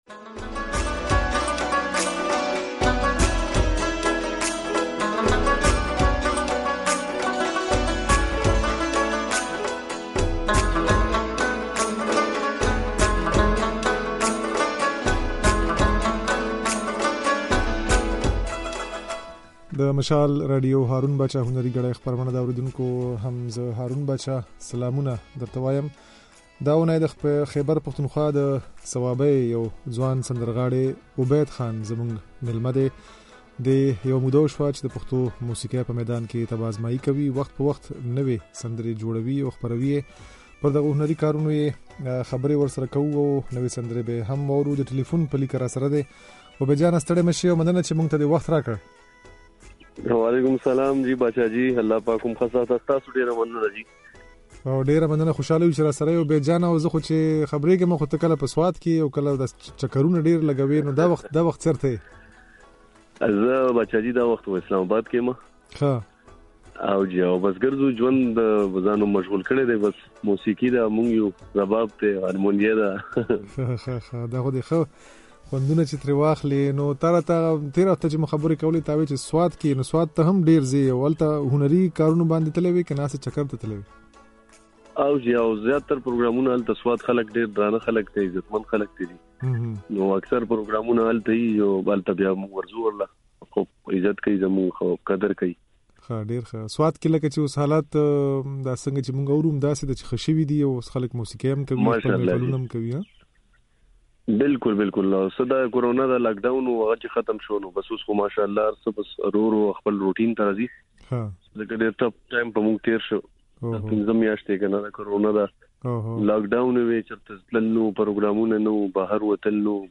دا خبرې او نوې سندرې يې د غږ په ځای کې اورېدای شئ.